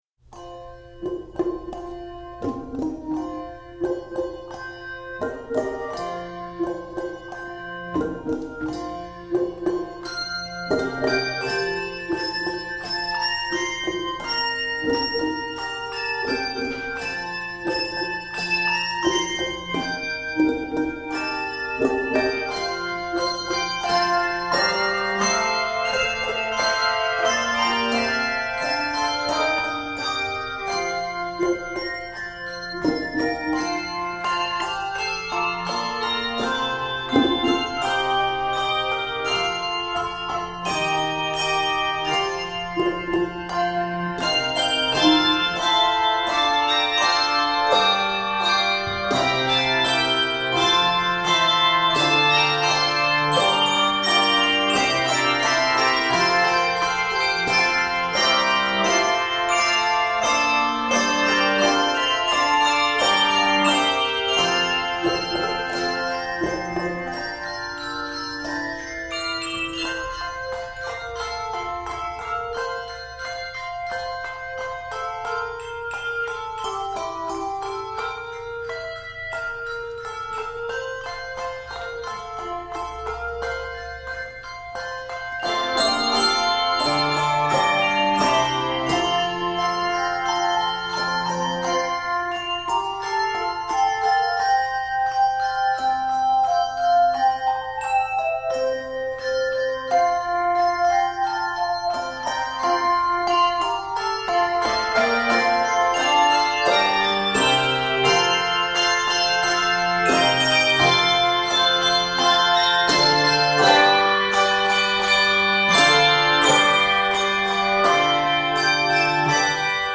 is an energetic setting